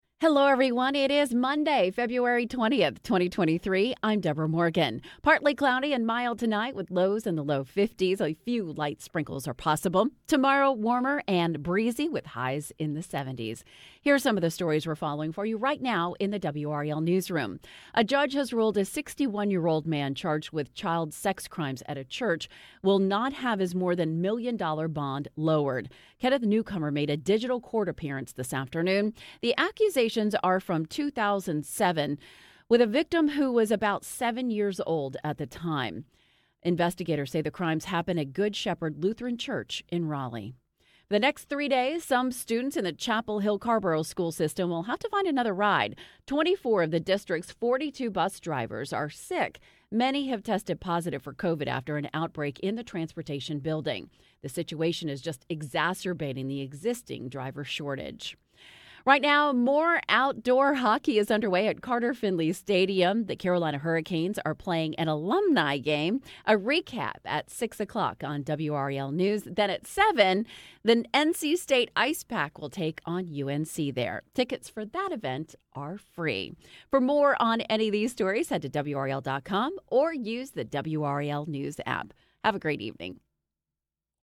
WRAL Newscasts